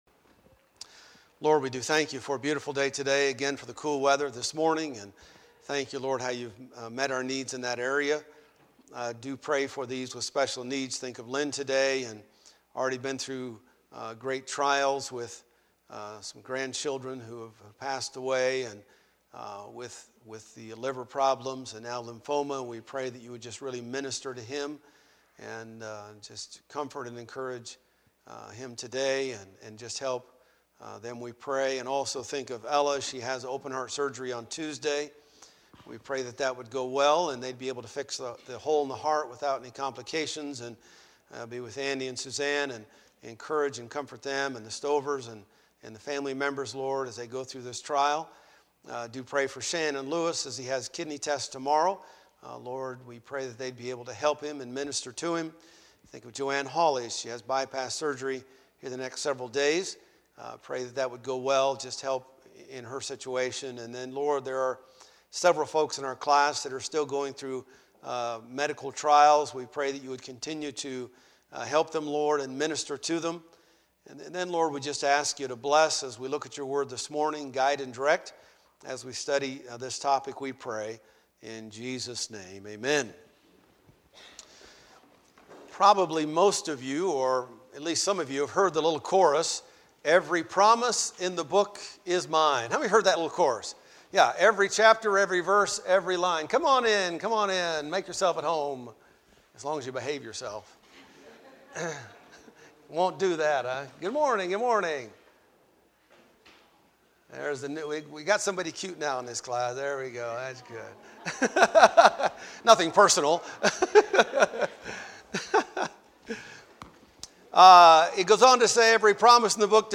2015 Sunday School Lesson